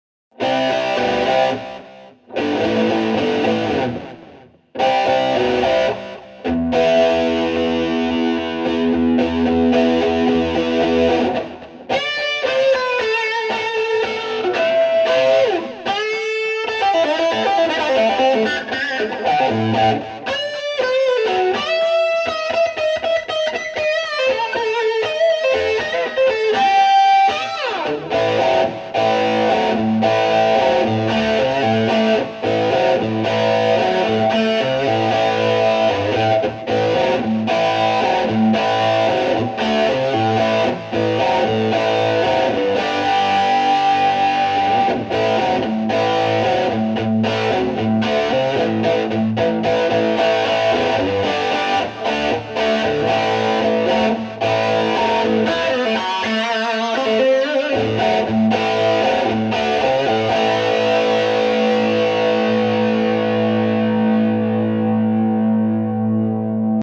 okey, on continue l'aventure et j'y ajoute maintenant un peu de reverbe et aussi un peu de delay; vu que la gratte est ici toute seule et isolé ces effets s'entendent évidemment très fortement, mais rassurez-vous, dans un mix complexe ils seront à peine perceptible quand c'est bien dosé mais cela créera un espace assez prononcé quand même; écoutez donc ce petit exemple suivant :
....pas mal, hein ?!!! un réel espace stéréophonique à été créé autour de la source mono (notre cerveau fera le reste pour les sentiments et images associés...)